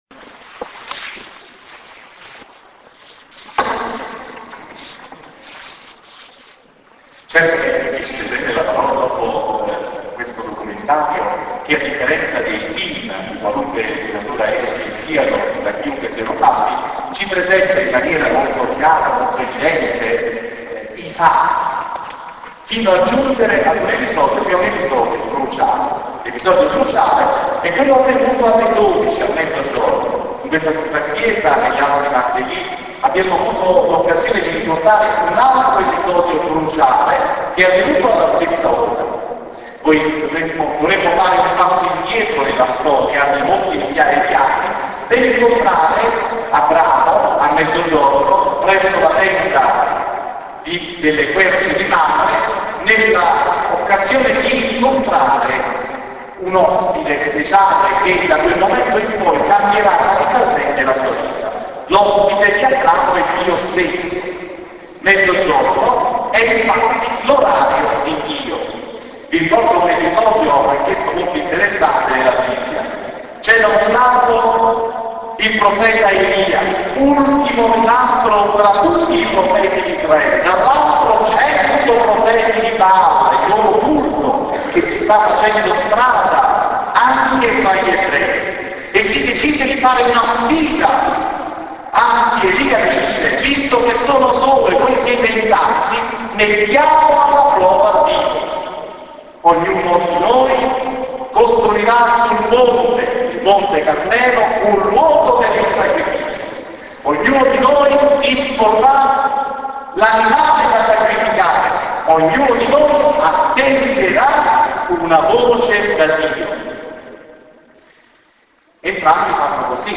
Marted� di San Marcello Marted� 3 marzo 2009 si � svolto nella chiesa di San Marcello il primo incontro di formazione per tutti gli operatori pastorali sulla vita e gli scritti di San Paolo. Il tema � stato �Una luce dal cielo� [At 9,13].
catechesi.mp3